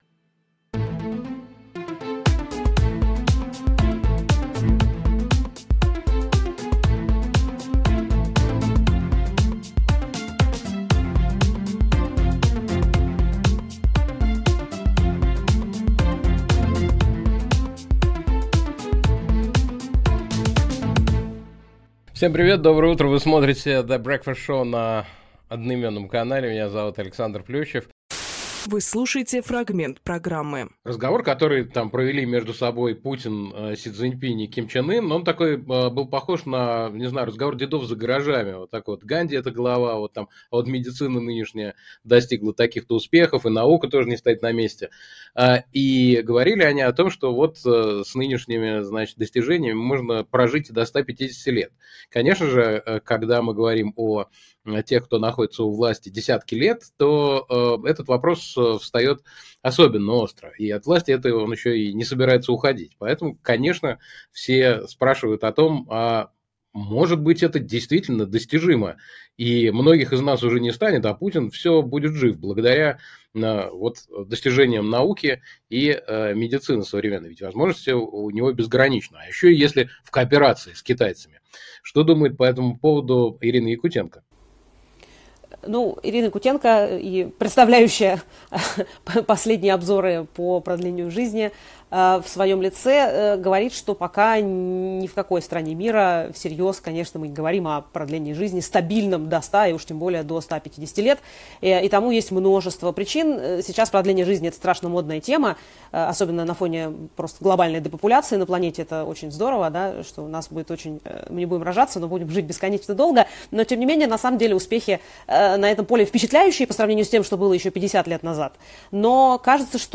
Александр Плющевжурналист
Фрагмент эфира от 04.09.25